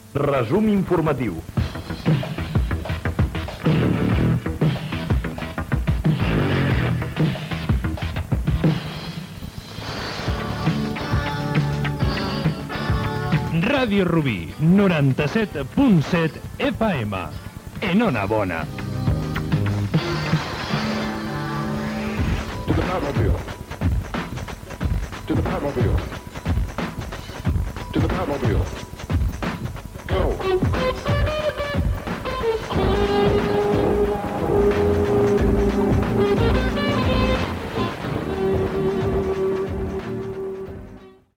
Careta de sortida
indicatiu, tema musical.